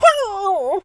dog_hurt_whimper_howl_05.wav